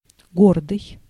Ääntäminen
France (Région parisienne): IPA: [sə fje]